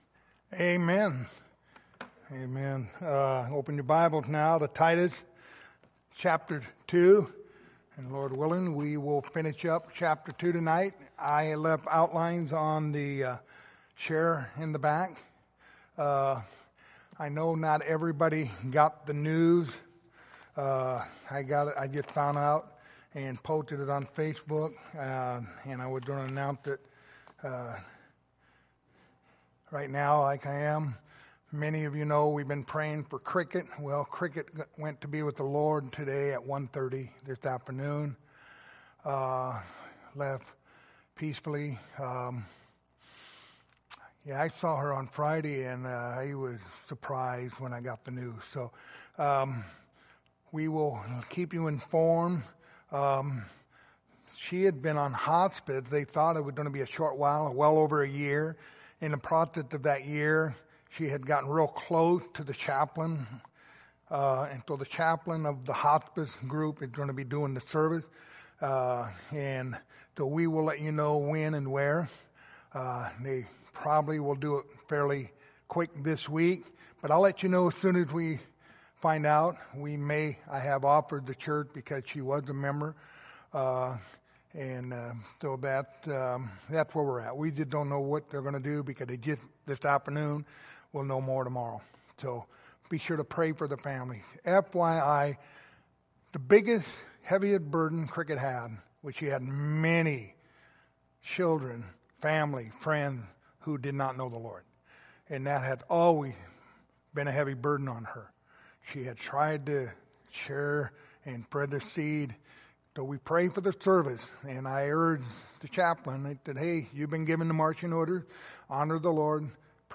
Passage: Titus 2:15 Service Type: Sunday Evening Topics